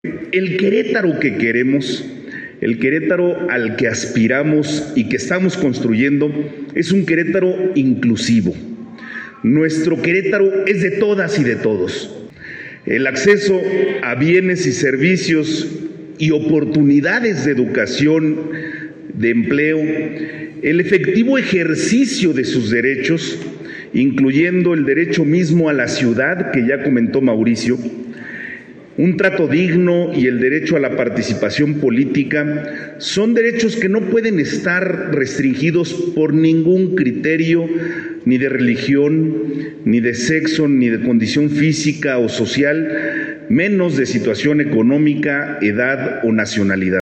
En el patio principal de la Delegación Centro Histórico, Luis Nava reiteró el compromiso con los sectores que por distintas razones han sufrido o sufren aún la discriminación sistemática, por ello dijo que es necesario que gobierno y sociedad en conjunto, transformen instituciones y promuevan paradigmas, prácticas sociales y laborales inclusivas.